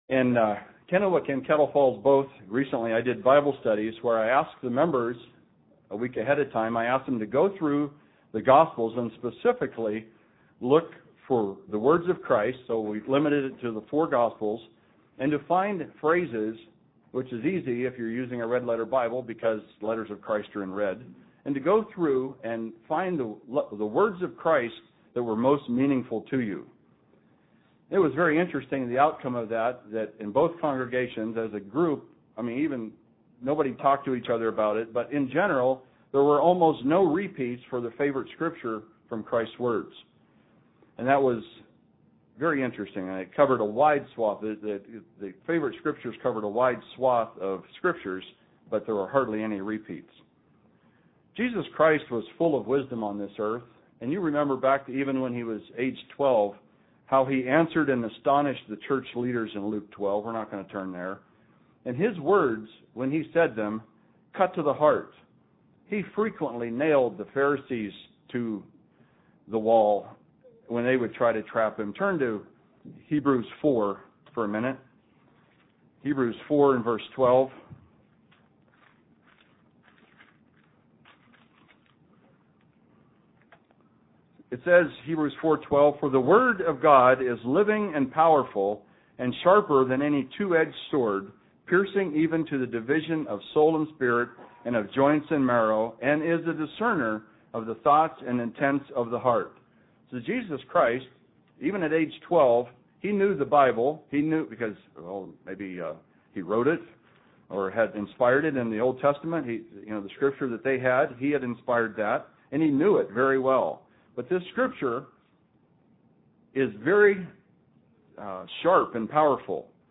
Given in Spokane, WA
UCG Sermon Studying the bible?